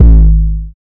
Clean [808].wav